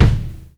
kick 7.wav